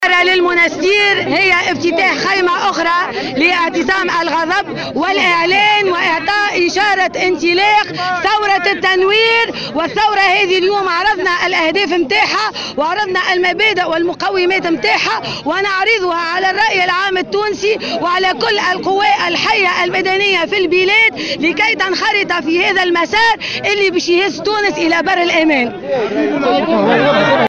نظم الحزب الدستوري الحر اليوم السبت اعتصام الغضب في المنستير احتجاجا على سياسة الحكومة الدّاعمة للتّنظيمات ذات العلاقة بالجرائم الإرهابية وتخاذلها في مكافحة الإرهاب وتبييض الأموال، وفق الحزب.
وقالت رئيسة الحزب عبير موسي في تصريح لمراسل "الجوهرة اف أم" إنه تم افتتاح خيمة أخرى لاعتصام الغضب لحزبها كما تم اعطاء اشارة انطلاق "ثروة التنوير"، على حد قولها.